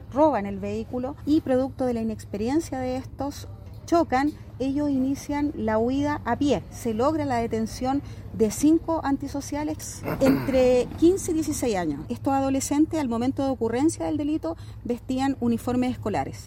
Así lo detalló la mayor de Carabineros